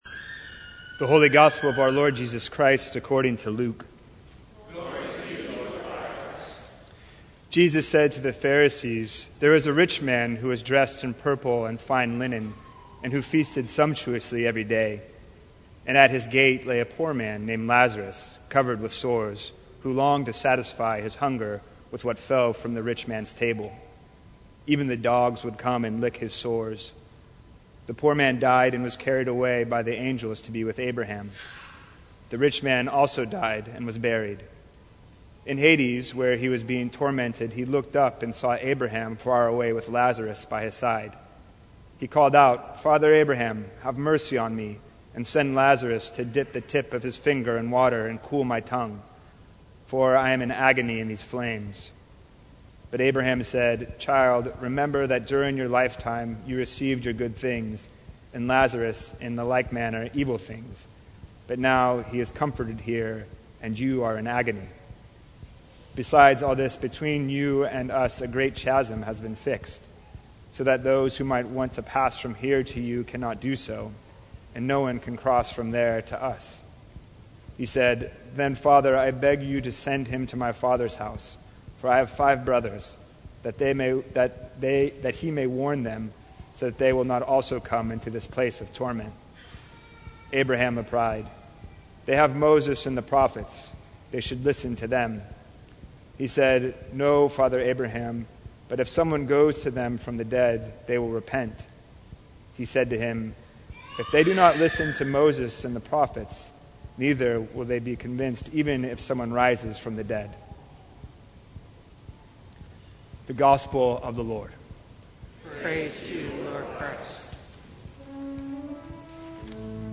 Sermons from St. Cross Episcopal Church Engaging on a Deeper Level Oct 09 2019 | 00:21:33 1x 00:00 / 00:21:33 Subscribe Share Apple Podcasts Spotify Overcast RSS Feed Share Link Embed